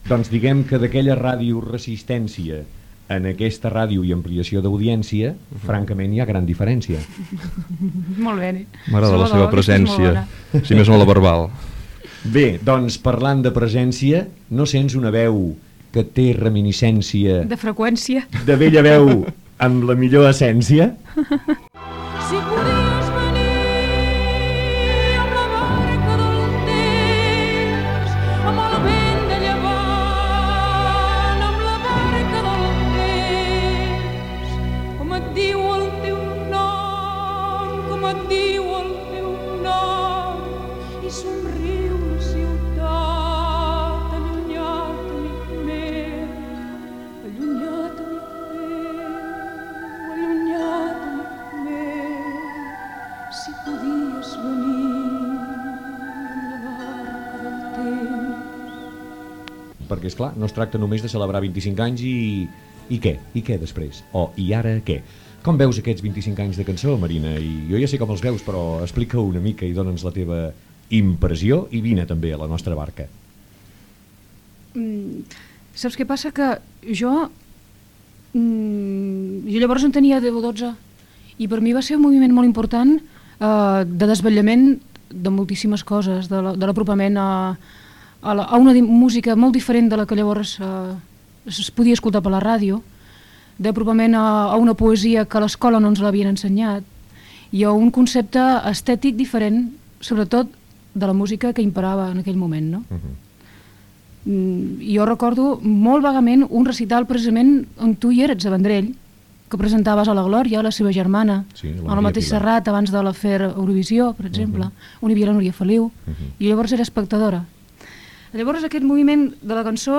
ca78b66b71d57ebf57c29c85b878aaf993ee0bda.mp3 Títol Ràdio Barça Cadena 13 Emissora Ràdio Barça Cadena 13 Cadena Cadena 13 Titularitat Privada nacional Nom programa Bon vent i barca nova Descripció Conversa amb la cantant Marina Rossell. Diàleg rimat, tema musical i conversa amb la cantant. Hora i tema musical.